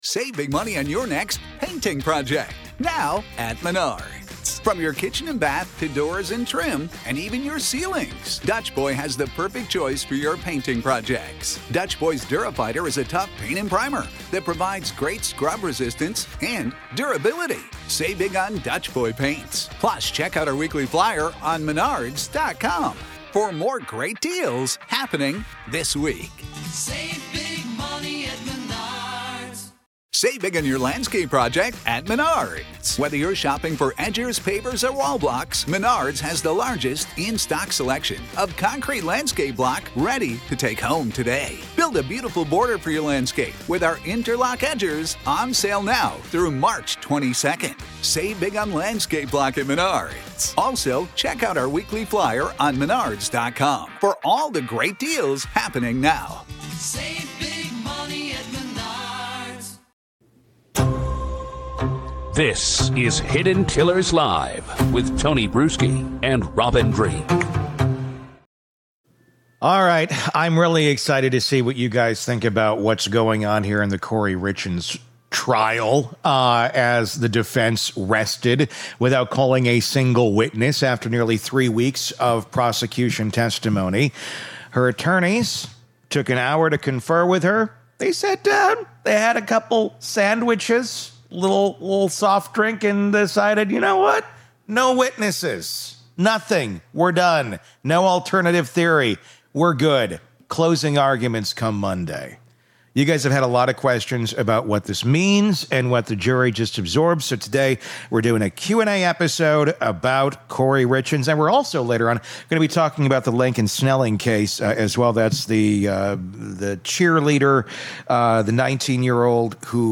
This is the complete pre-verdict listener Q&A — three full conversations covering everything this trial produced.